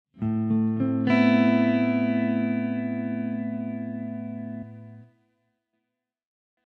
My solution is to set up a bunch of instances of LA convolver on multiple busses in AU LAB.
Here are the IR’s used in bus 3:
bus3 is the tone.